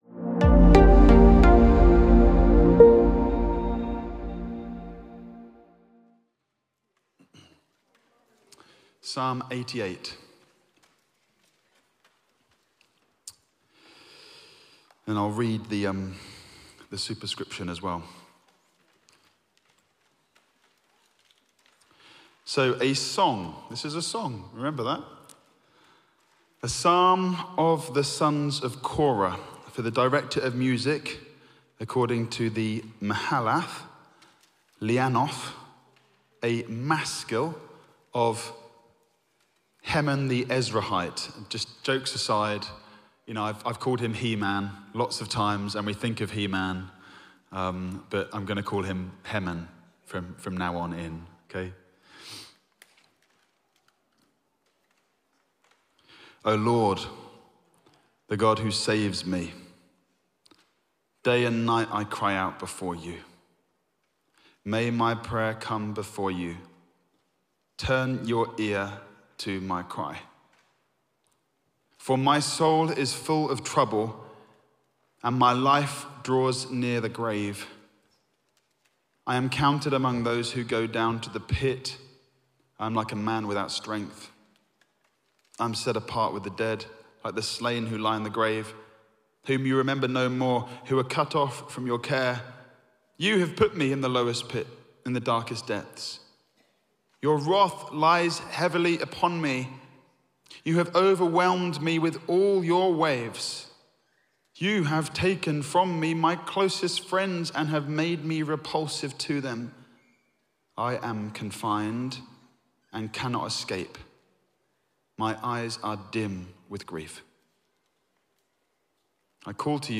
The Evening Sermon 25.05.25 - All Saints Peckham
Audio Sermon